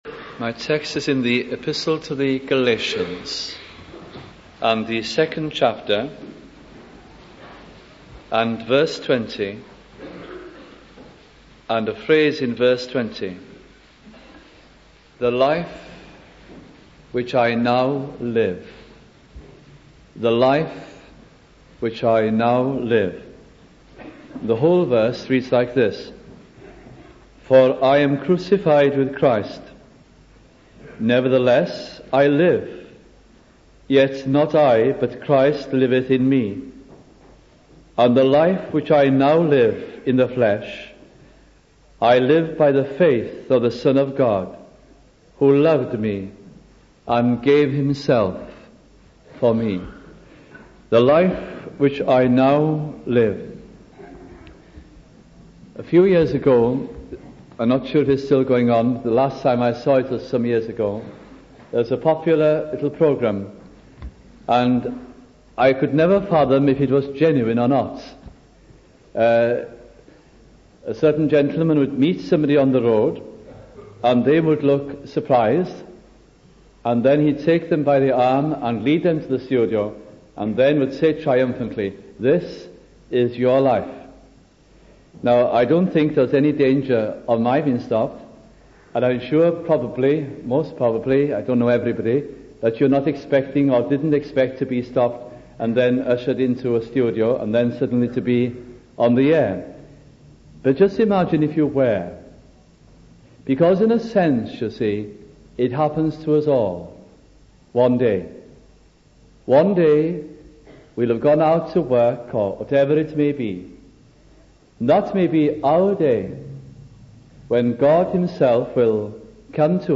» Galatians Gospel Sermons